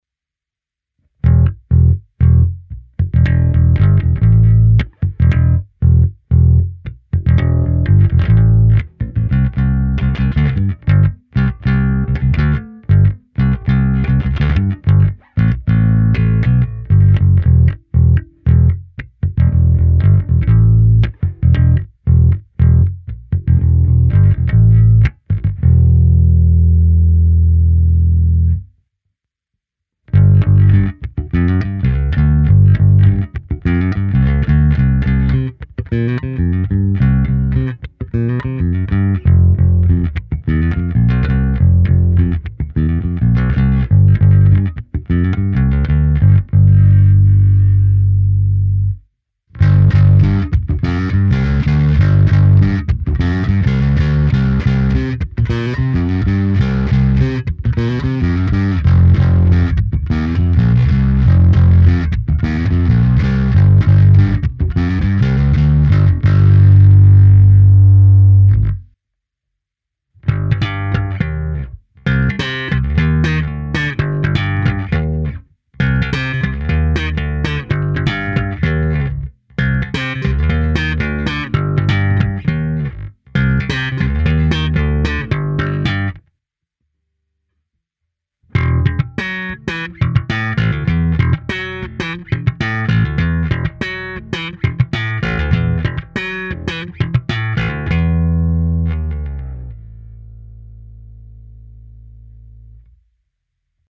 Zde bylo použito i zkreslení a hra slapem.
Simulace aparátu – roundwound